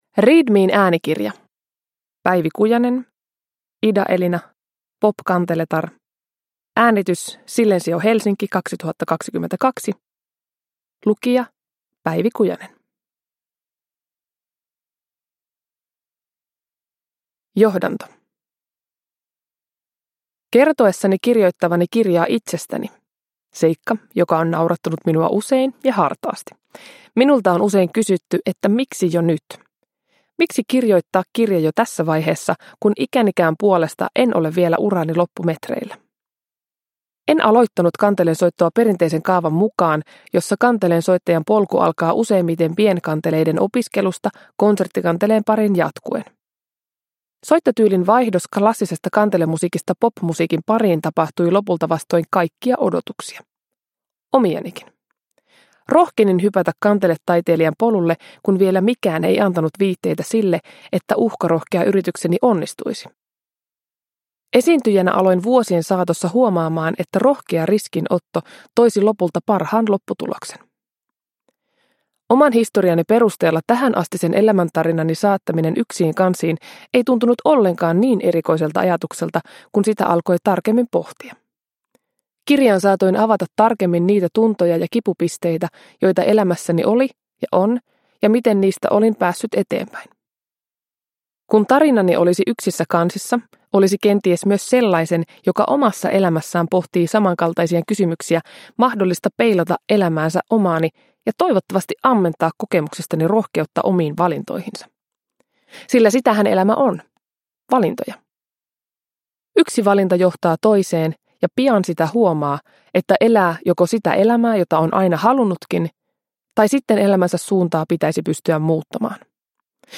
Ida Elina – Ljudbok